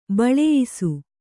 ♪ baḷeyisu